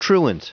Prononciation du mot truant en anglais (fichier audio)
Prononciation du mot : truant